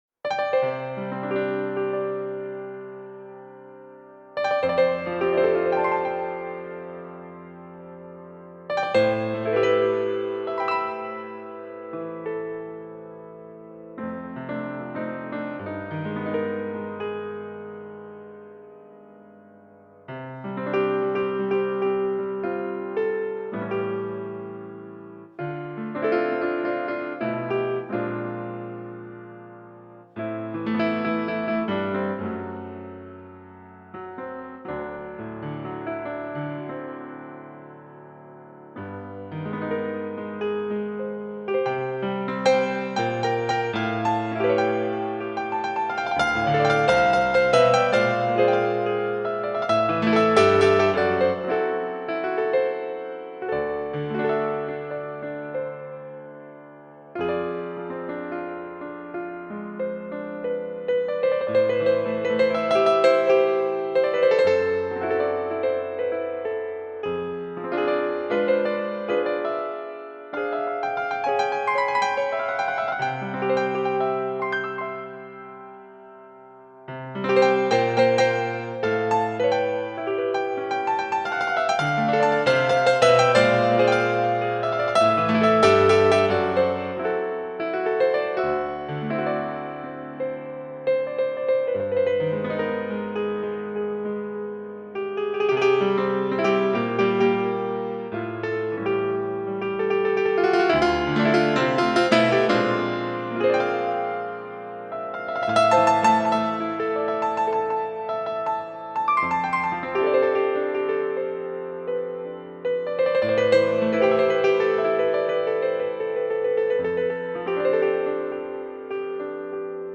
(Major) Improvisation